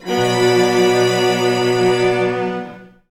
Index of /90_sSampleCDs/Zero-G - Total Drum Bass/Instruments - 2/track57 (Strings)